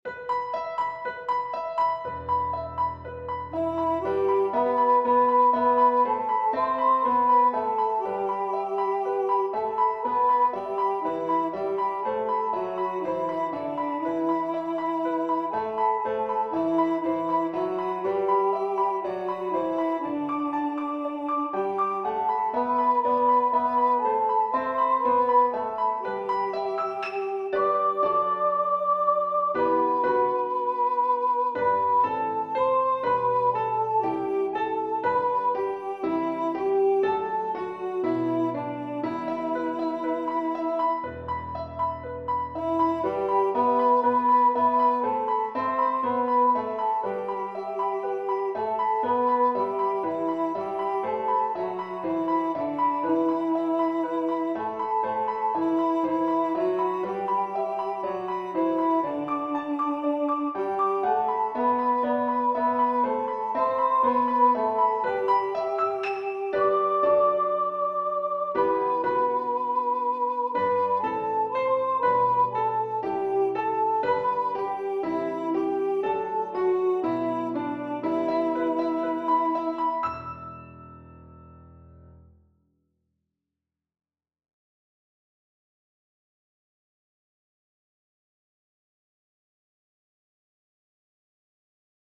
original piano accompaniment for congregational singing
Piano accompaniment for congregational singing.